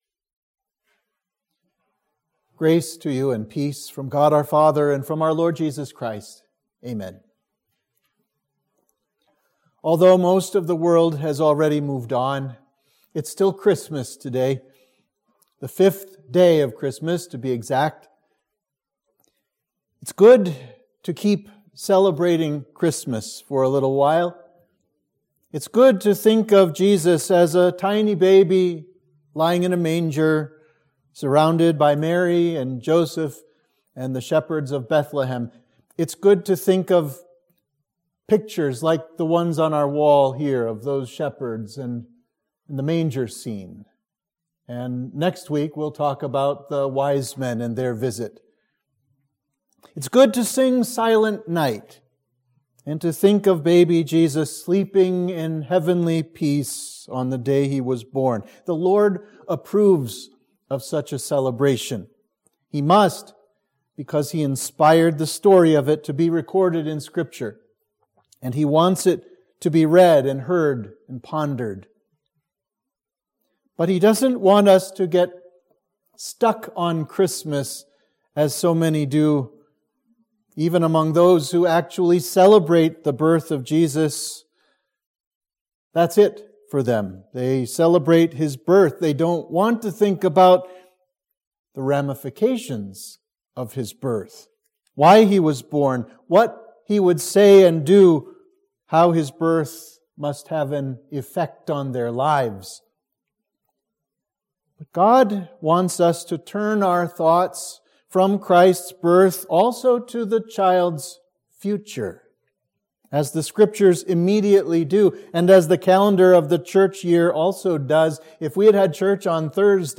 Sermon for Christmas 1